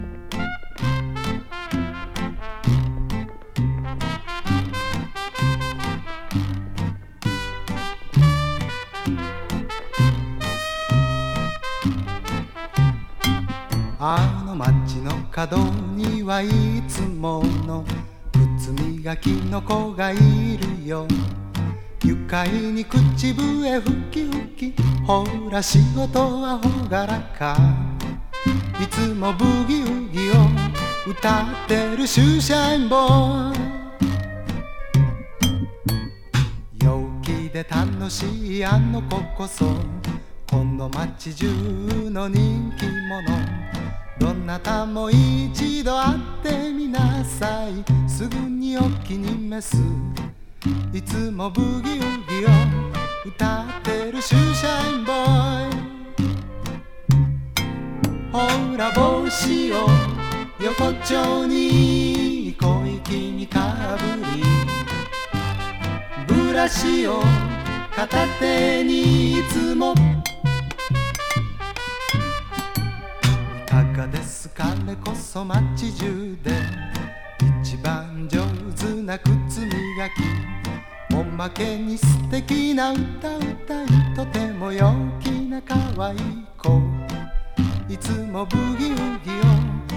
オールドタイミーなジャズやカントリ～ブルーズ（時にサザンロック）に日本語詞を乗せた軽快でほのぼのとした楽曲が並ぶ1枚。